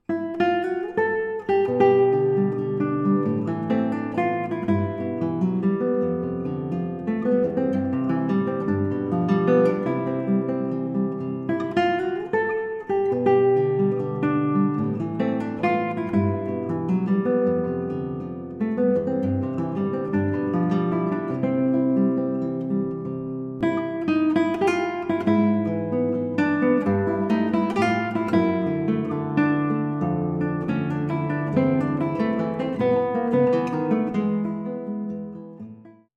Solo Guitar and Guitar with String Orchestra